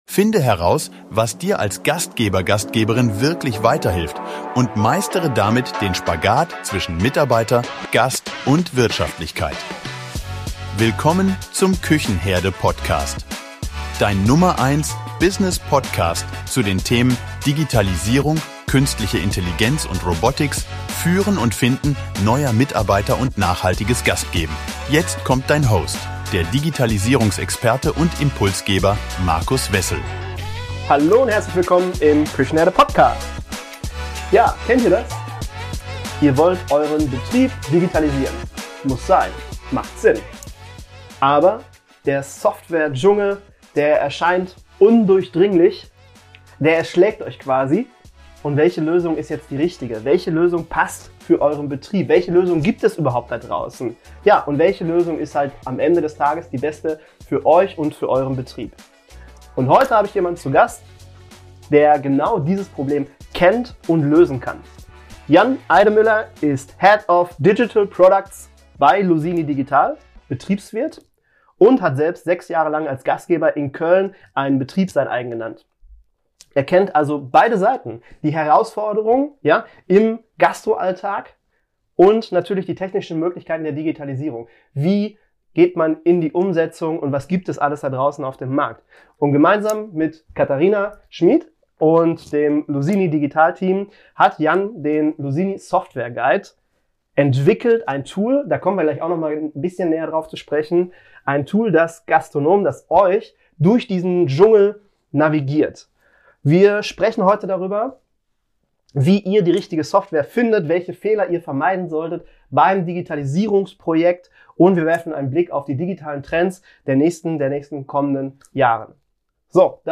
Digitalisierung ohne Stolperfallen – Interview